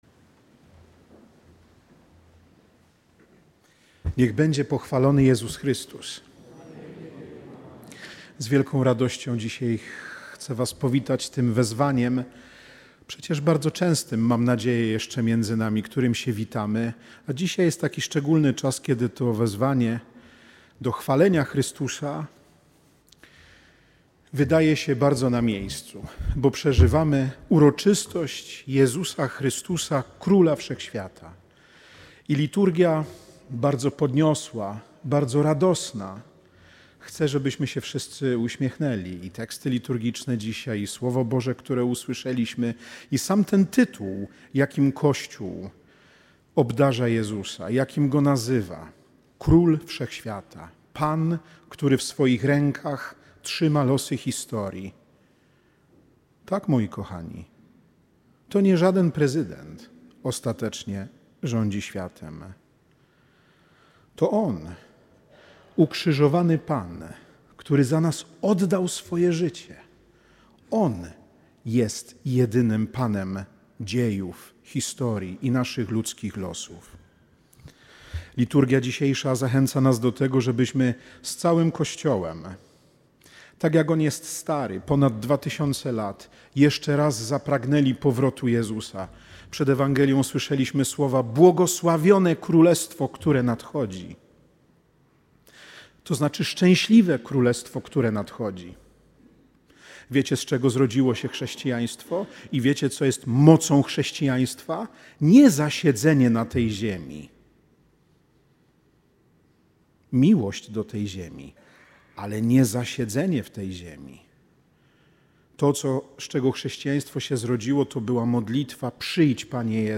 Posłuchaj całej homilii: Uroczystość Chrystusa Króla Wszechświata Pobierz